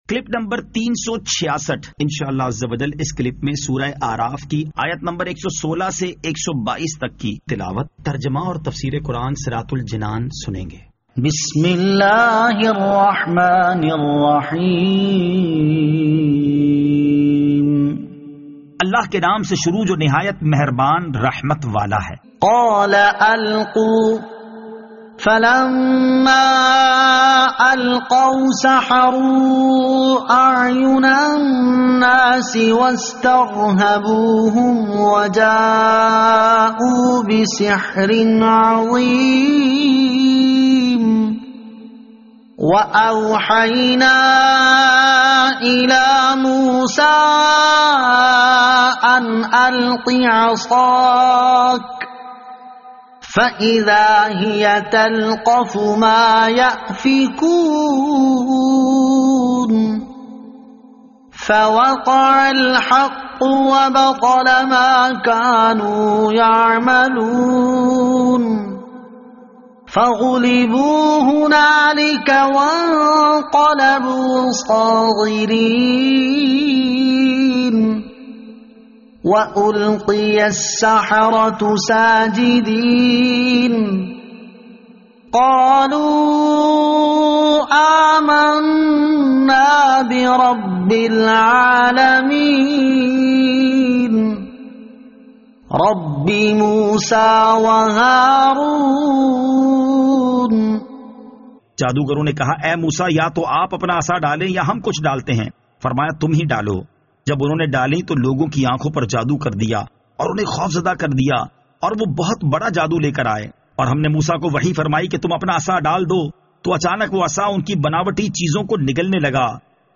Surah Al-A'raf Ayat 116 To 122 Tilawat , Tarjama , Tafseer